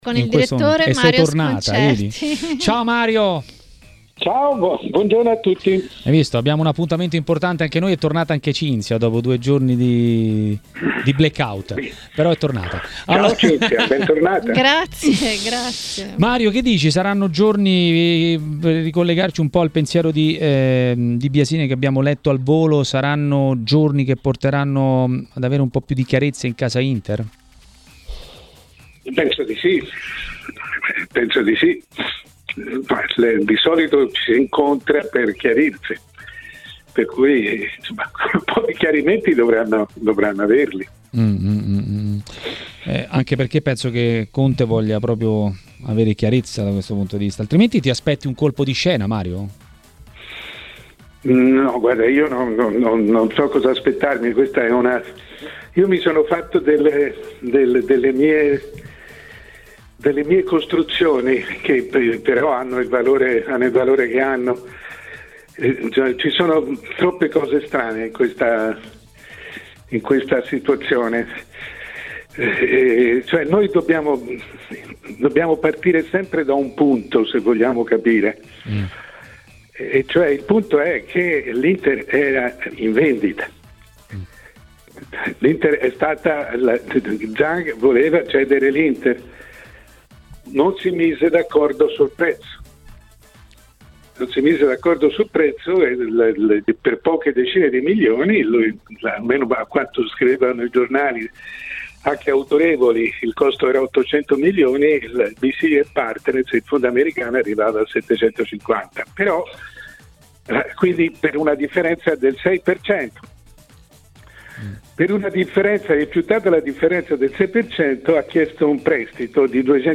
A TMW Radio, durante Maracanà, il direttore Mario Sconcerti ha parlato soprattutto di Inter e Coppa Italia.